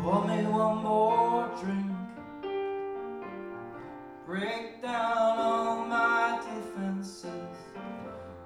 That’s roomy not boomey.